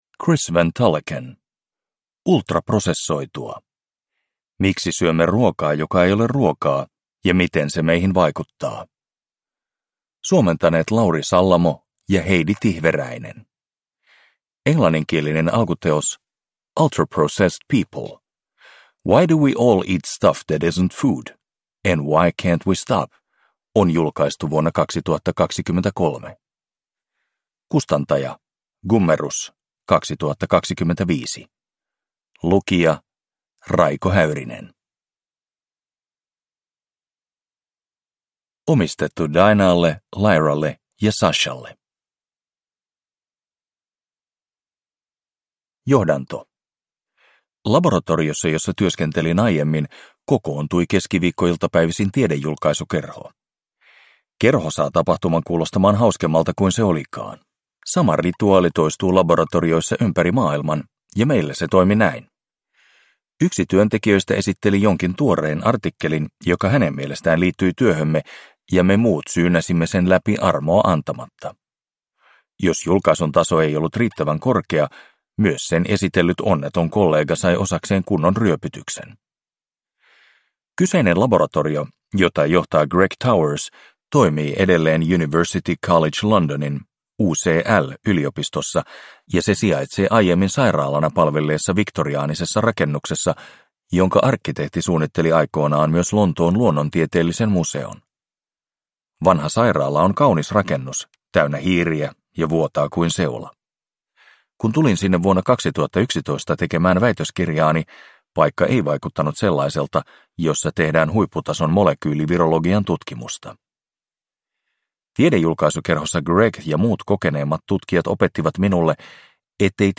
Ultraprosessoitua – Ljudbok